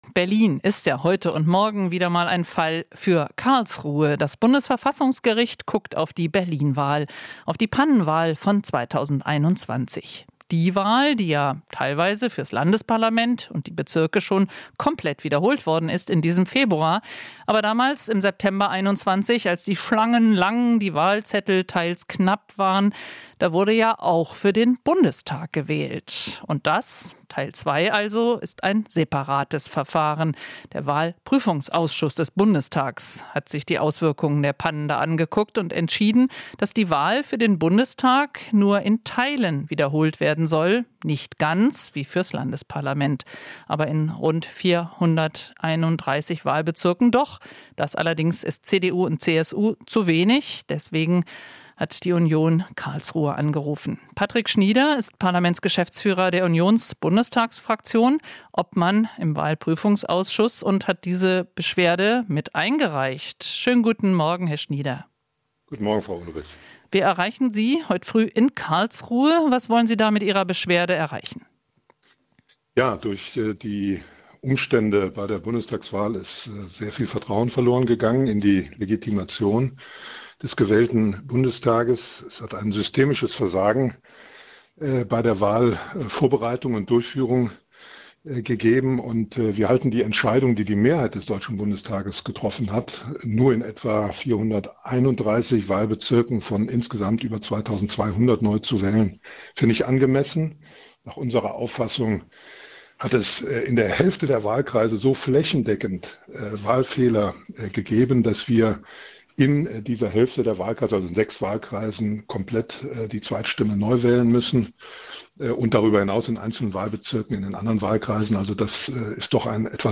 Interview - Schnieder (CDU): "Wir halten uns strikt an Voraussetzung der Wahlgesetzes"